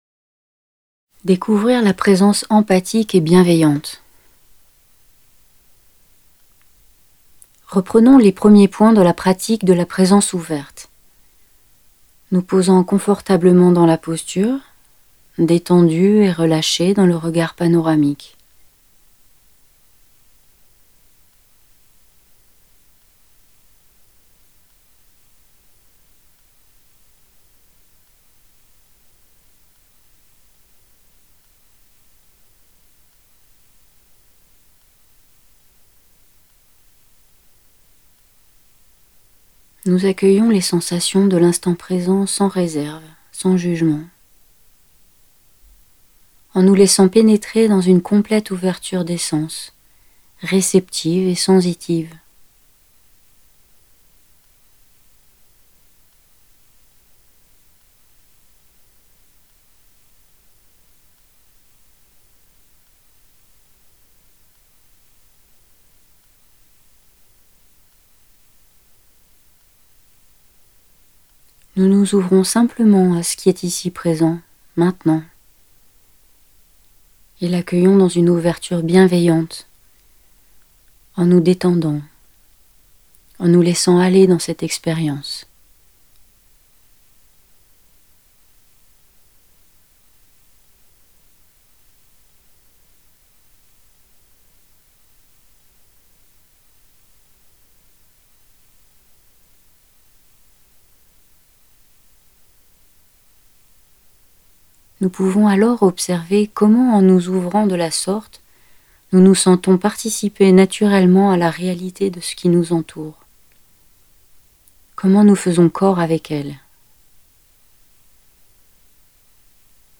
Découvrir la présence empathique et bienveillante : 5 minutes de méditation en onze points
Audio femme - Pratique 4
4.AUDIO-4-P4-FEMME.mp3